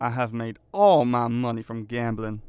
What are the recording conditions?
Update Voice Overs for Amplification & Normalisation